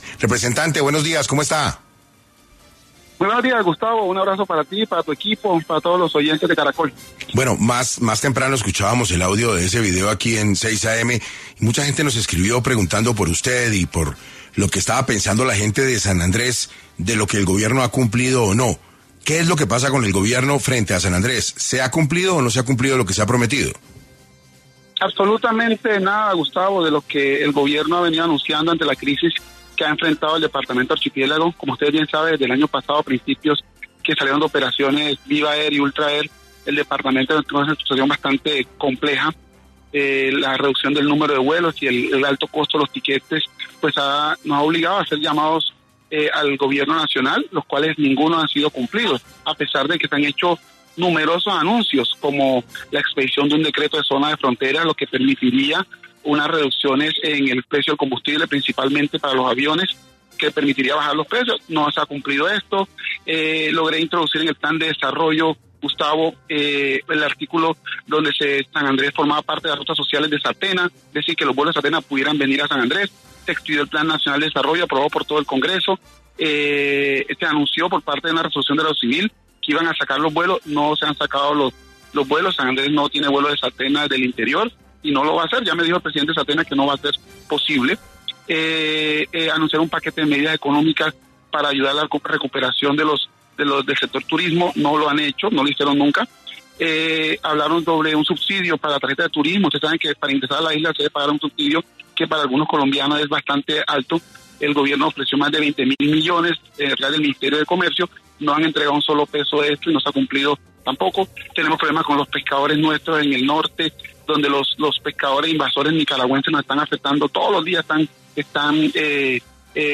En 6AM de Caracol Radio habló Jorge Méndez, representante a la Cámara por San Andrés y Providencia quien denuncia el incumplimiento de Petro frente a la situación turística de San Andrés